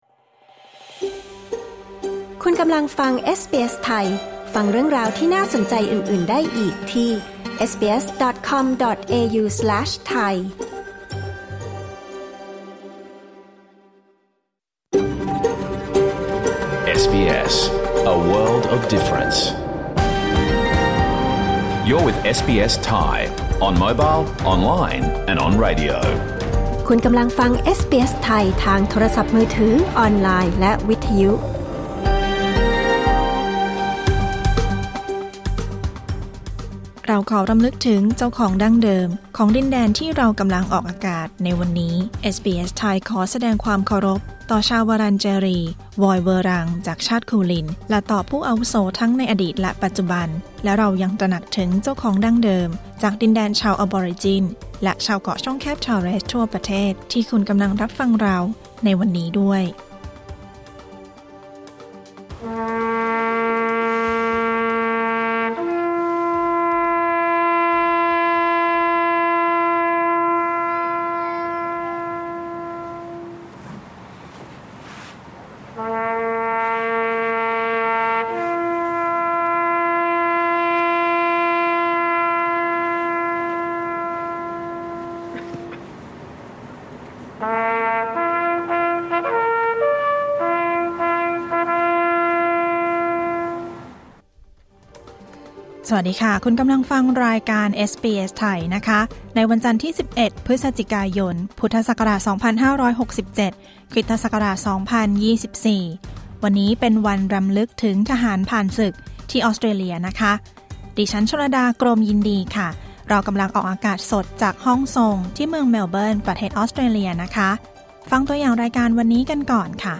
รายการสด 11 พฤศจิกายน 2567